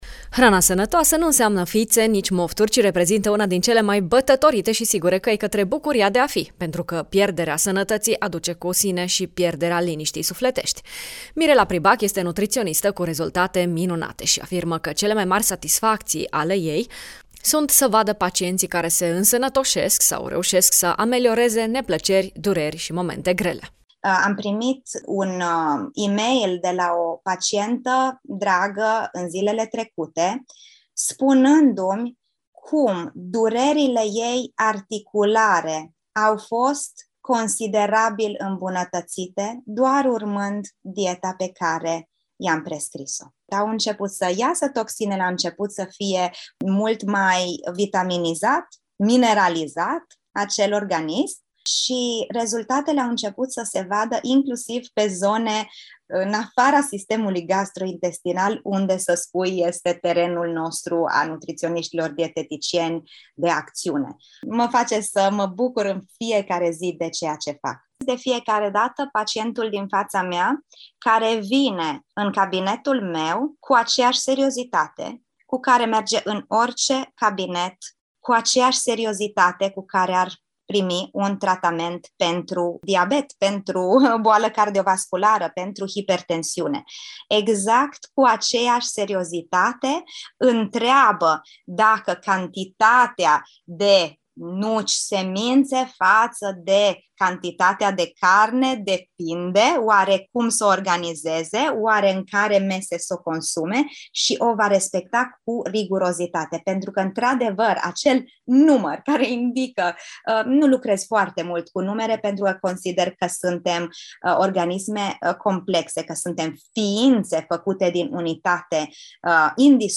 nutritionist